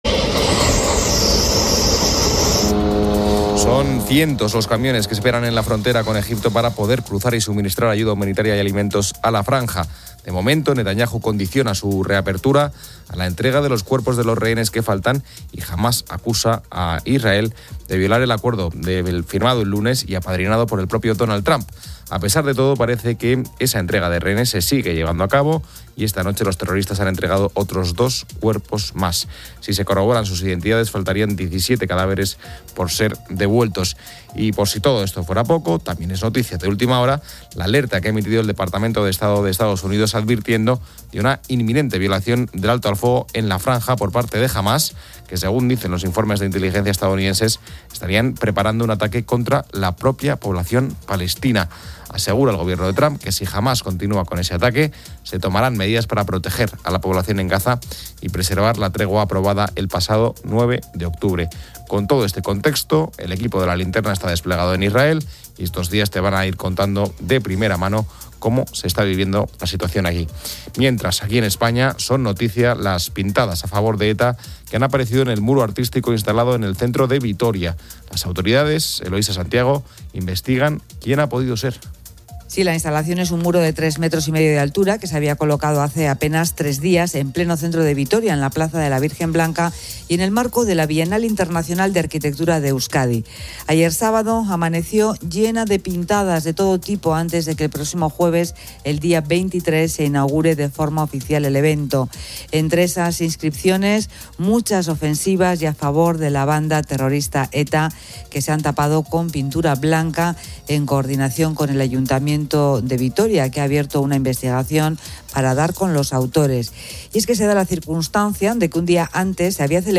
El programa aborda noticias que incluyen la salida de Pablo Venegas de La Oreja de Van Gogh, el deseo de Andy (de Andy y Lucas) de iniciar una carrera en solitario, y el fallecimiento de Morante de la Puebla. Un invitado canta sobre un complemento vitamínico y sobre la Reina Letizia.